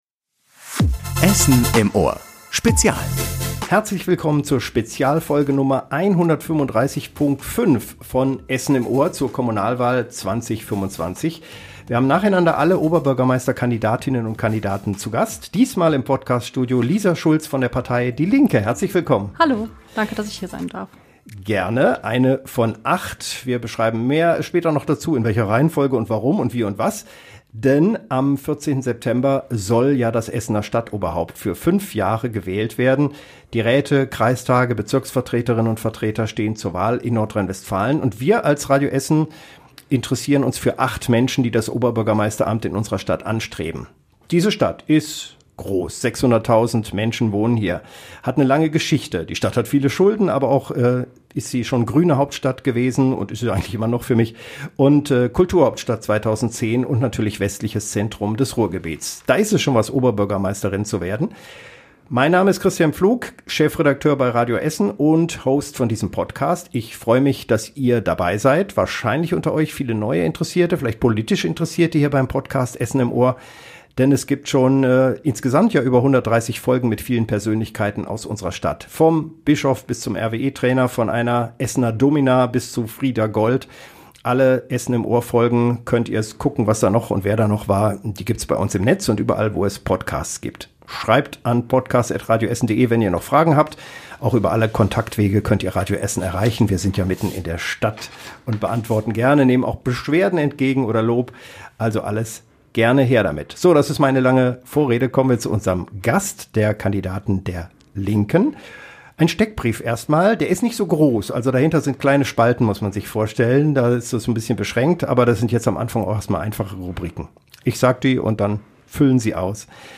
~ Essen im Ohr - Der Talk mit Persönlichkeiten aus der Stadt Podcast